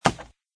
woodplastic3.mp3